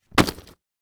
household
Duffle Bag Drop Cement Floor 2